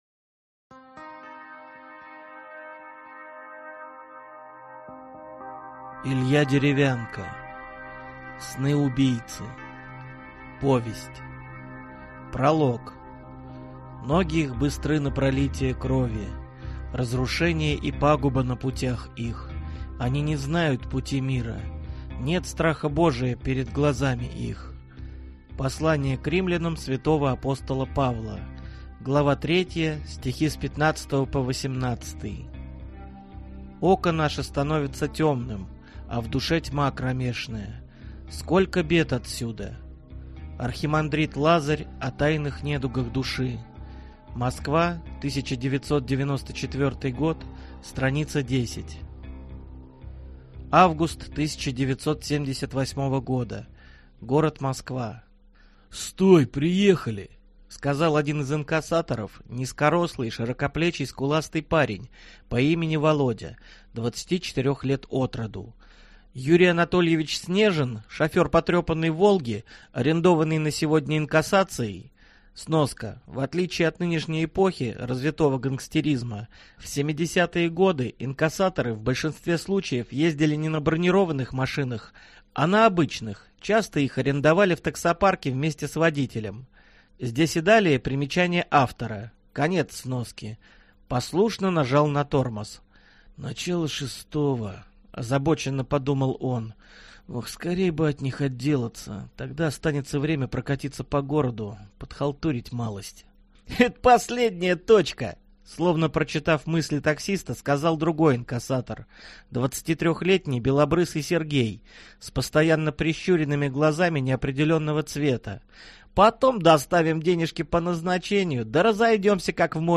Аудиокнига Сны убийцы | Библиотека аудиокниг